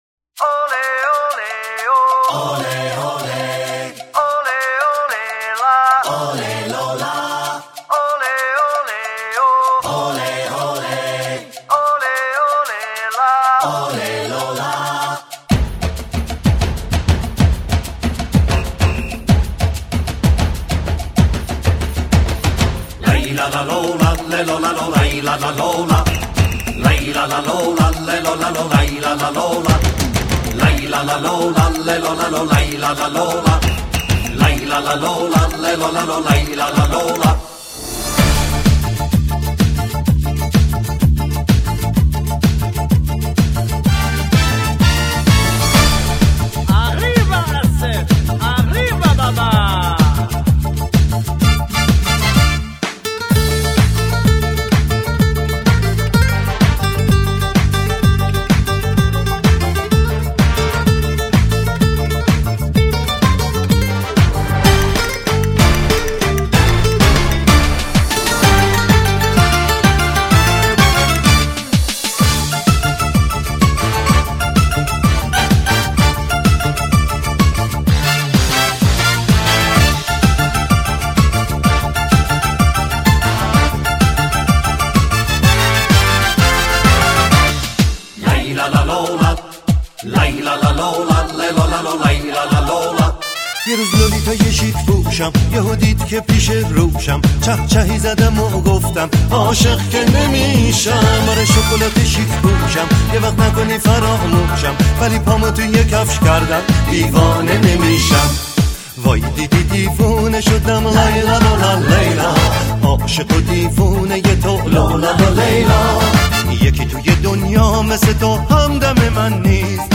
اهنگ شاد ایرانی